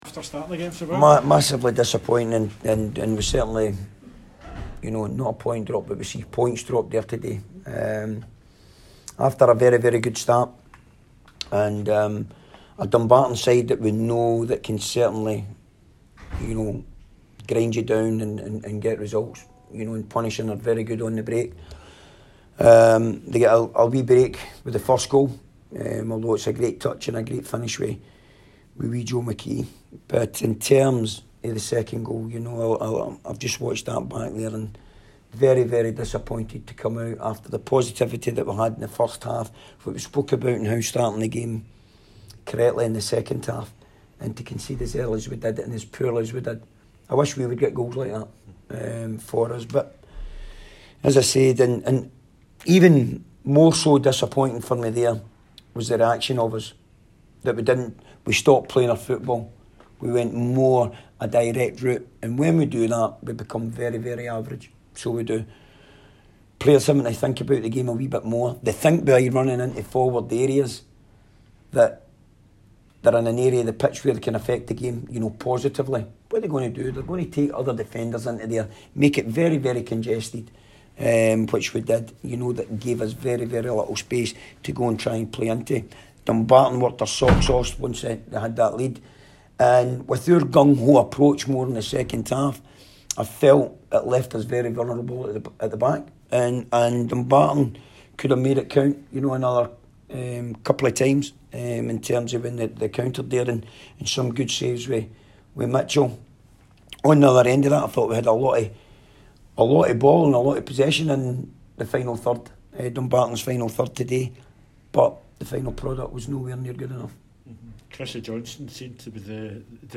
press conference after the Ladbrokes League 1 match.